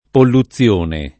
polluzione [ pollu ZZL1 ne ] s. f.